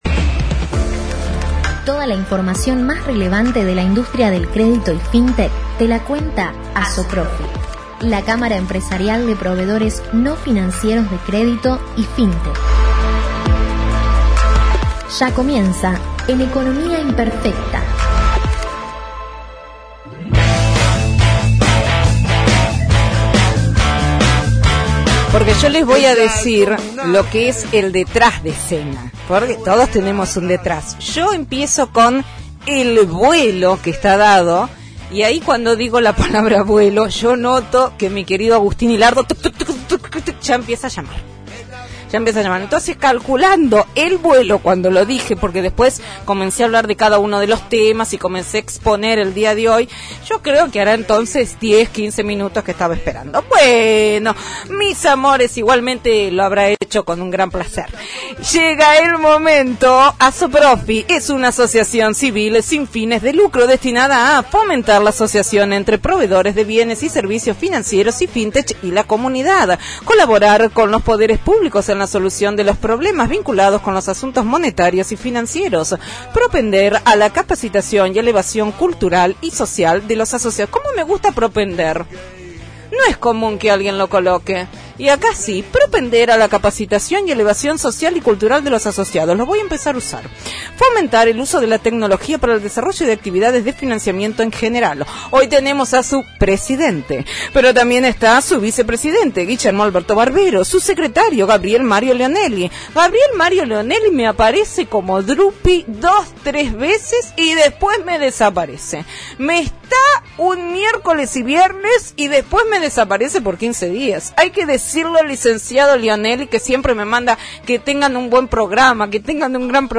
ASOPROFI – COLUMNA RADIAL – RADIO AM 1420 Viernes 06/08/2021 – ” Compliance y Gobierno Corporativo – Una necesidad o un invento de los asesores”